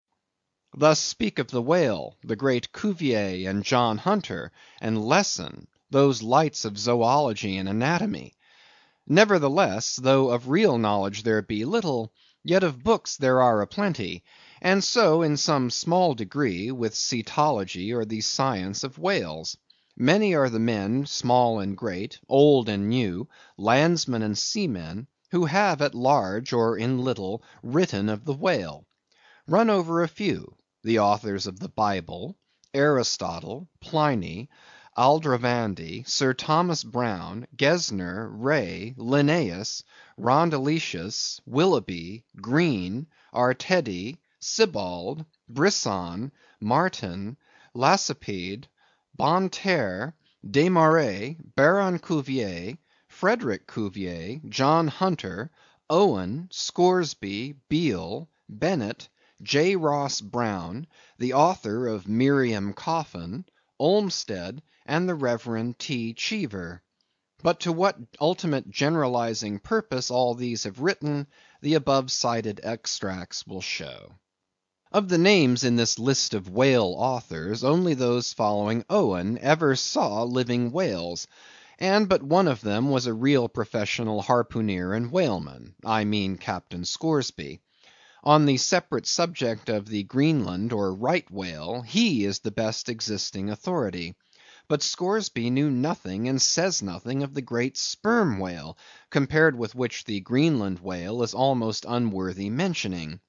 英语听书《白鲸记》第369期 听力文件下载—在线英语听力室